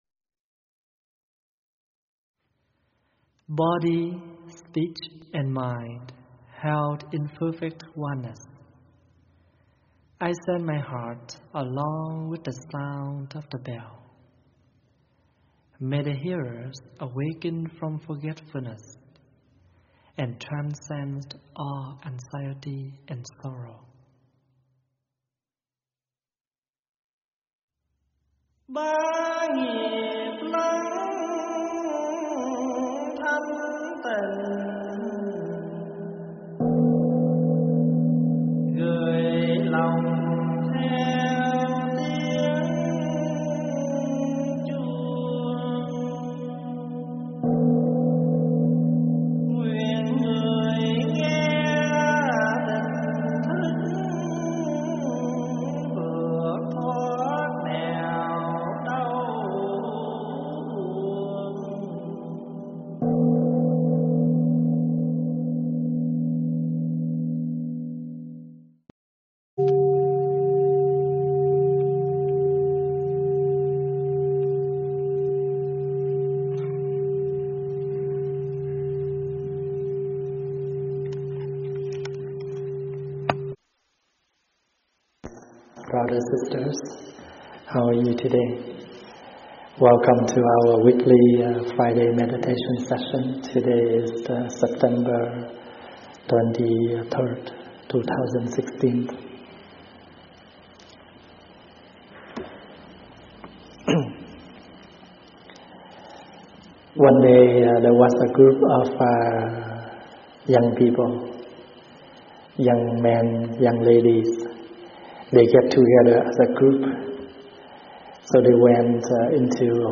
Mp3 thuyết pháp Finding Yourself - ĐĐ.